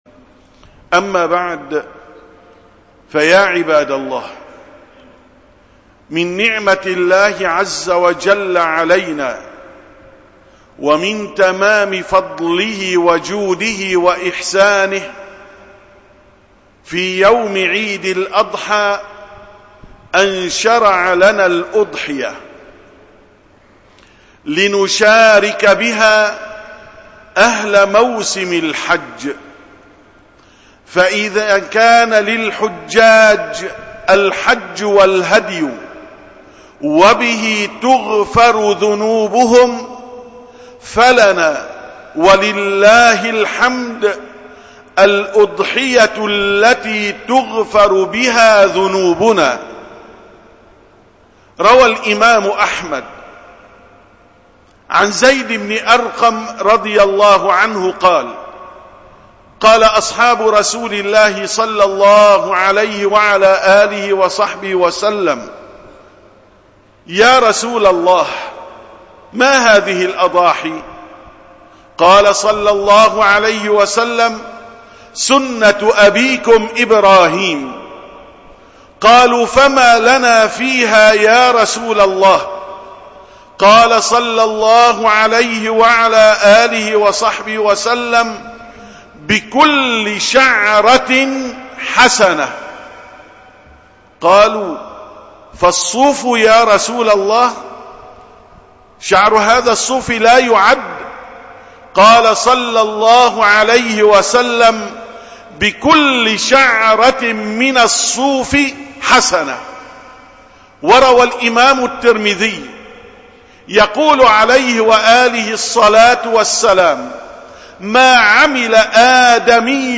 666ـ خطبة الجمعة: «فَطِيبُوا بِهَا نَفْسَاً»